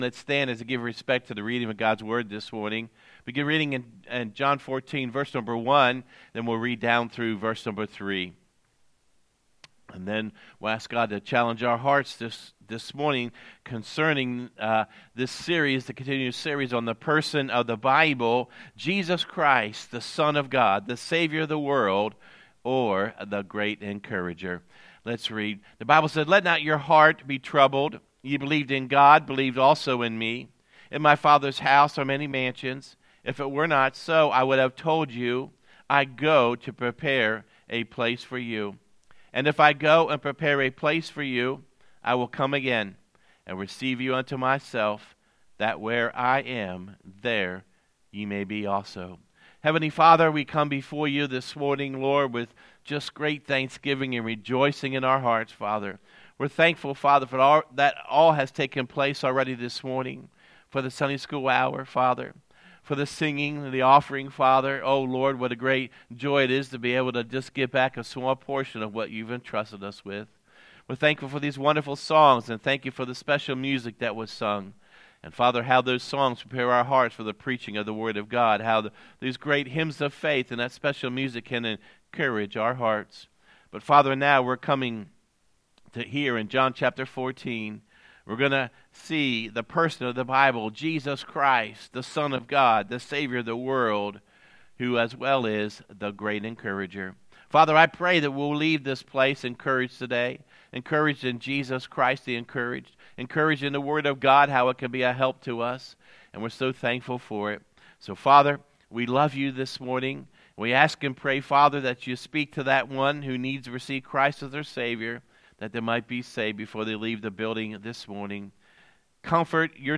Sermons | Sharon Baptist Church